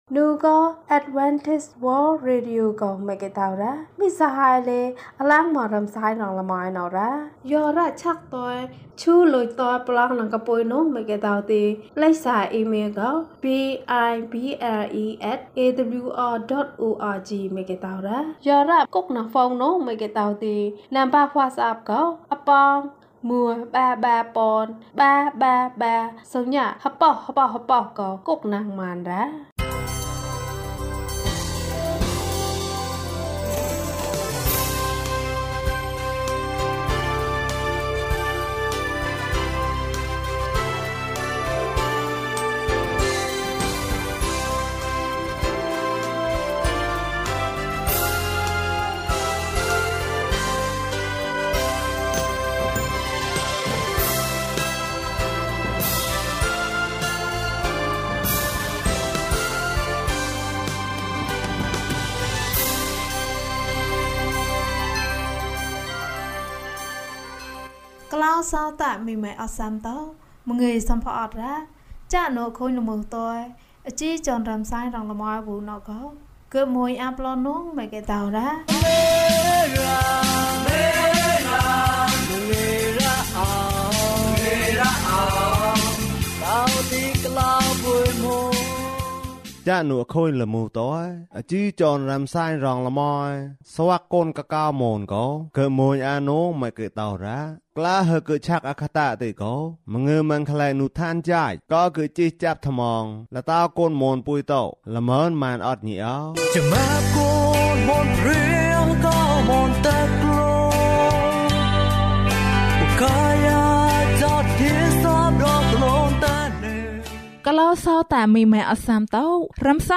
ဘုရားသခင်နှင့်ဝေးဝေးမနေပါနှင့်။ ကျန်းမာခြင်းအကြောင်းအရာ။ ဓမ္မသီချင်း။ တရားဒေသနာ။